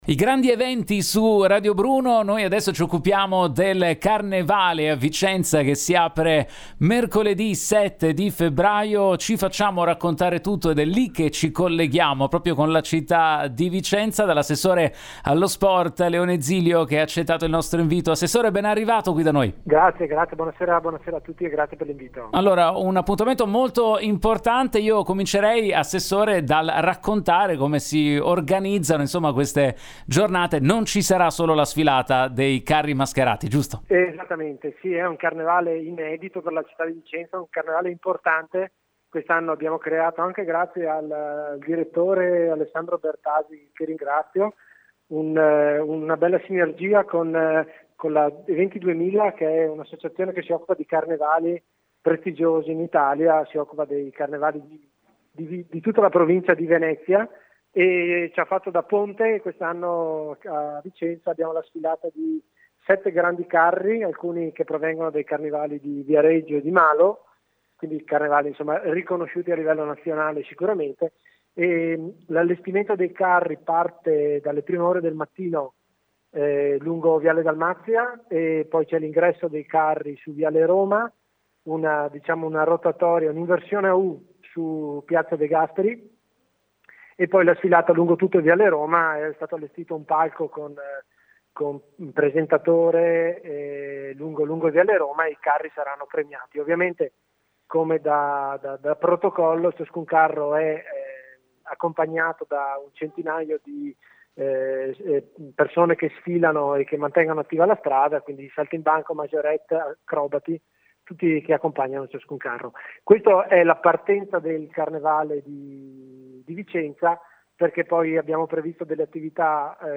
Home Magazine Interviste Torna il Carnevale di Vicenza